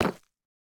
Minecraft Version Minecraft Version snapshot Latest Release | Latest Snapshot snapshot / assets / minecraft / sounds / block / deepslate_bricks / step5.ogg Compare With Compare With Latest Release | Latest Snapshot
step5.ogg